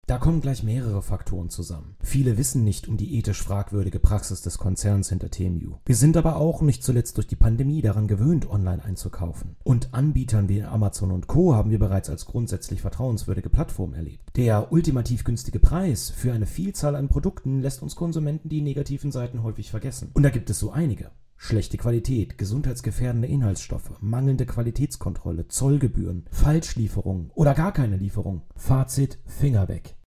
Er ist Experte für Psychologie im Marketing.